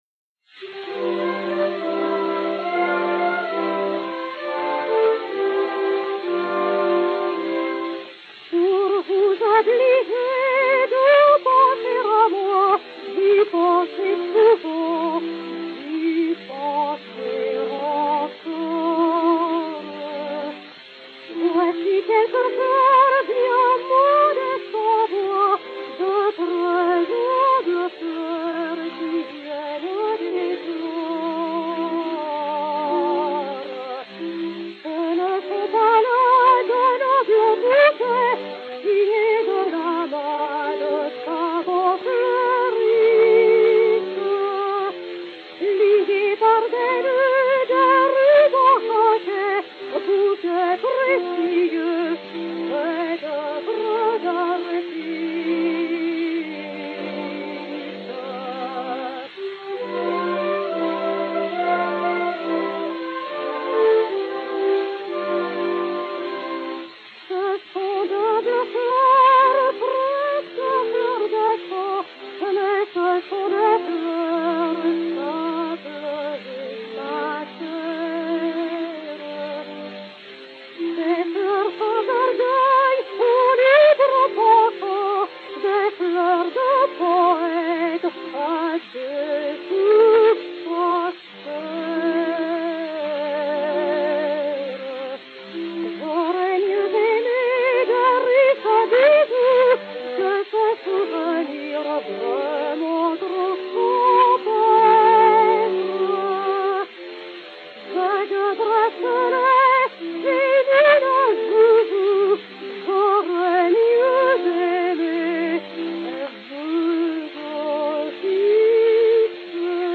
mélodie (par.
Jeanne Marié de l'Isle et Orchestre
Zonophone X 83050, mat. 5090o, enr. à Paris en 1905